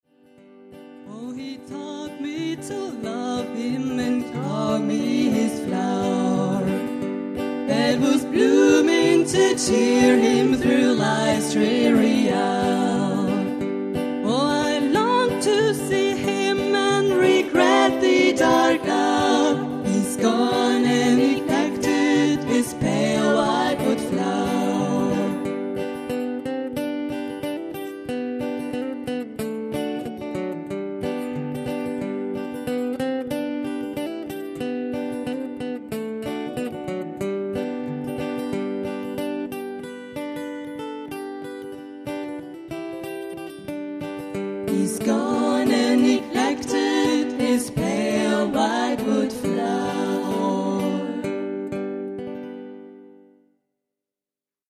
S eit Februar 2006 bereichert das Gesangstrio der "Schwarzwasserperlen" mit dem für die "Carter Family" typischen harmonischen Satzgesang die Konzerte von Bandana - Sound of Johnny Cash. Mit dem Klassiker "Wildwood Flower" (live mitgeschnitten beim Memorial Concert anlässlich des Geburtstages von Johnny Cash am 26.